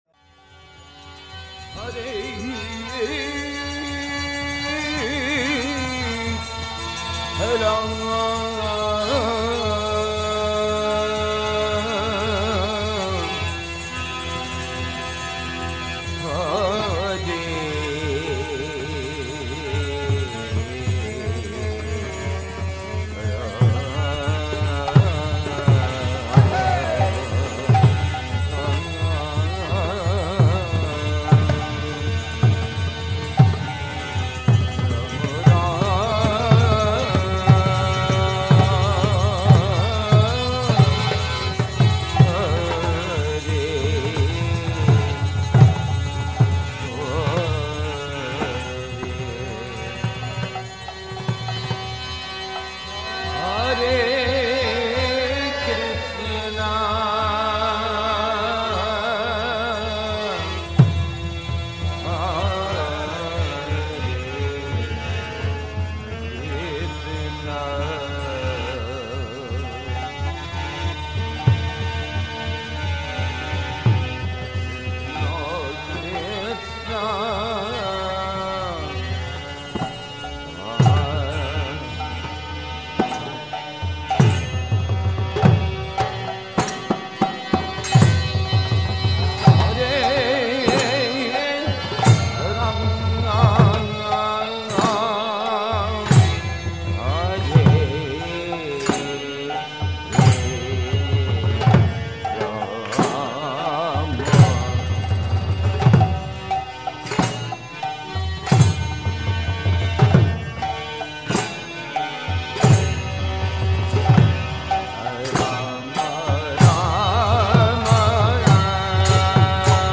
temple kirtans